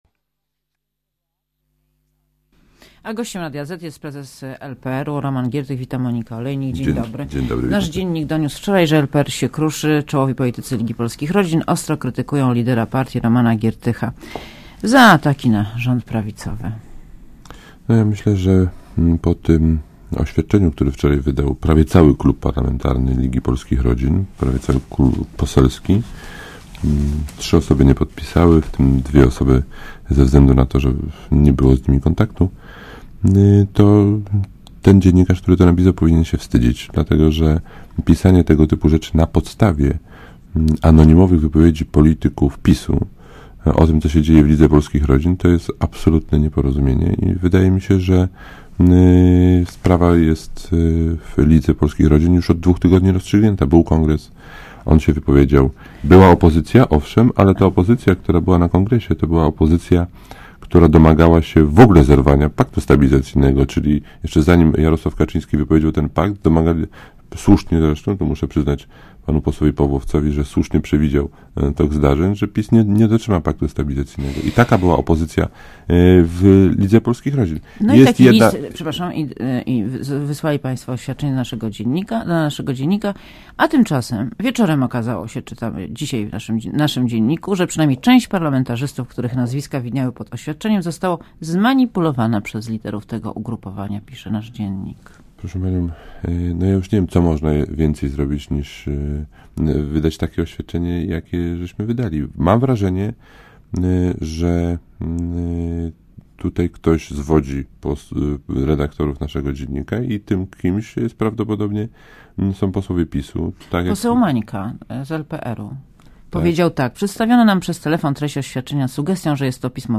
Gościem Radia ZET jest prezes LPR-u Roman Giertych.